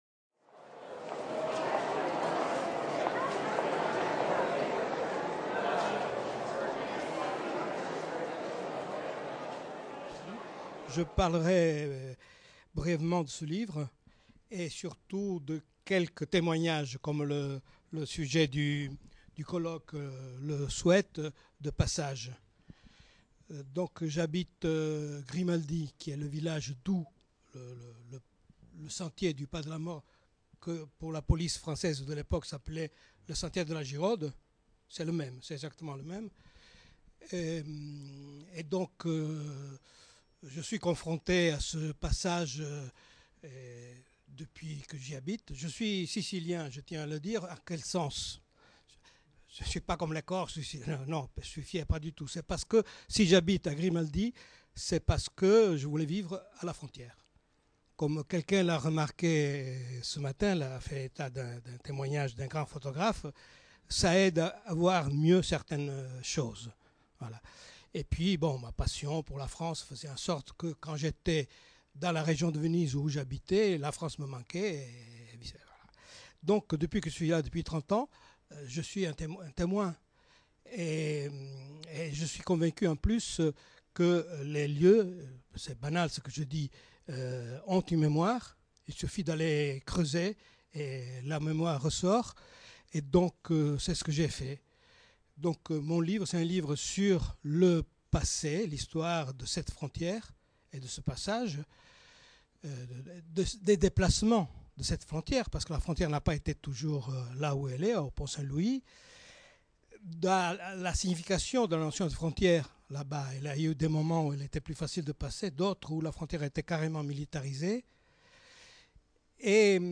La frontière franco-italienne des Alpes-Maritimes, étudier les récits de traversées Journée d’étude organisée par l’Urmis et l’Observatoire des Migrations dans les Alpes-Maritimes à la MSHS de Nice, le jeudi 6 décembre 2018.